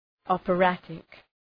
Προφορά
{,ɒpə’rætık}